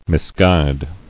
[mis·guide]